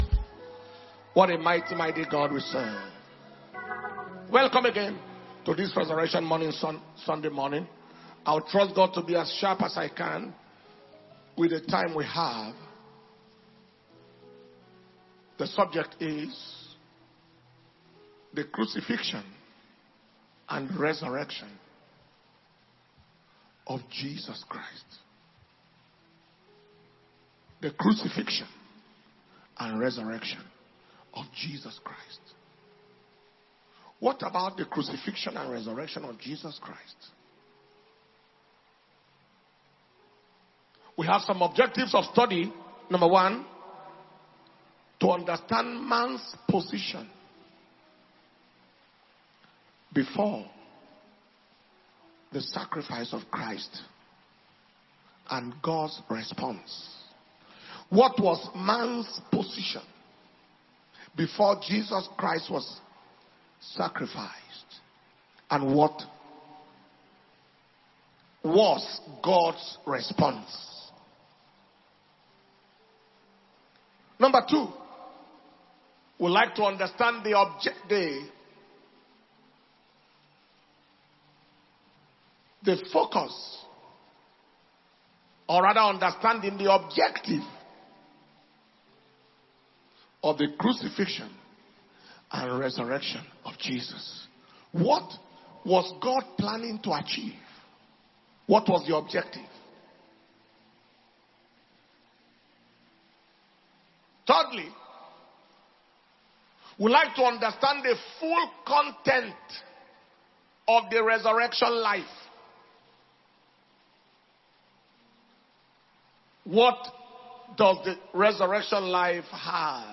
Easter Sunday Service